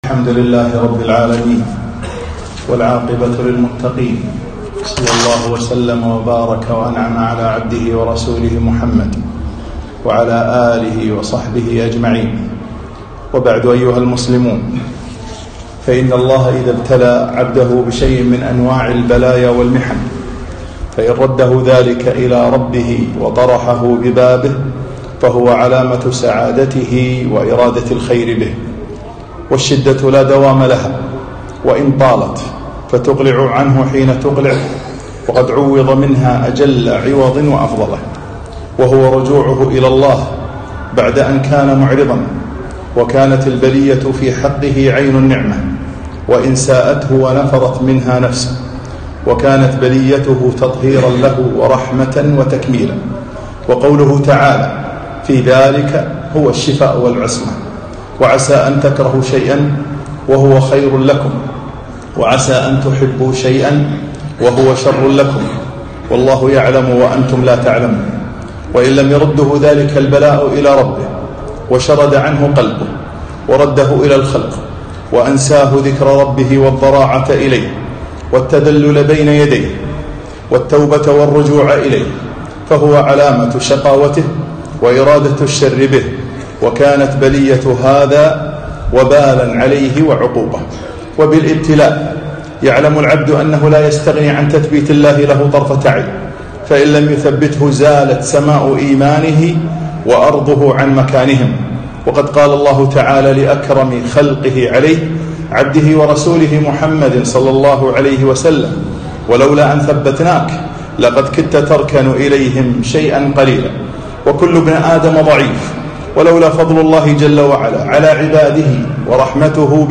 خطبة التعامل مع الابتلاء